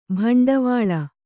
♪ bhaṇḍavāḷa